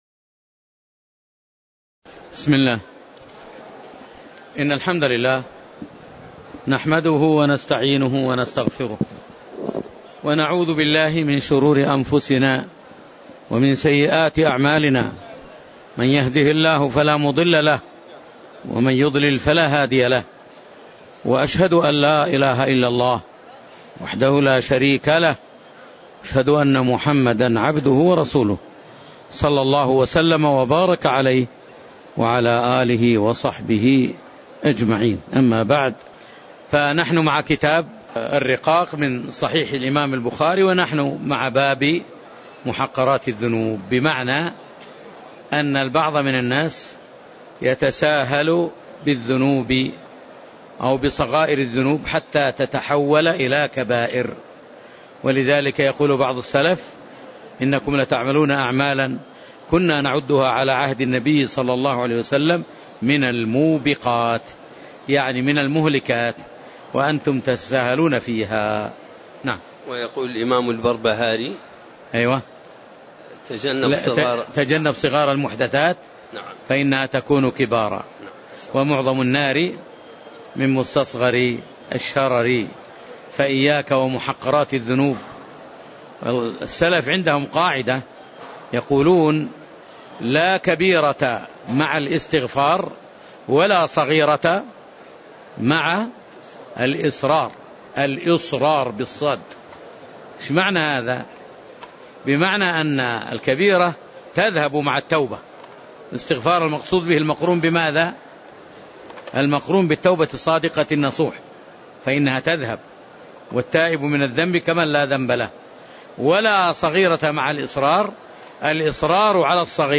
تاريخ النشر ١٤ رمضان ١٤٣٩ هـ المكان: المسجد النبوي الشيخ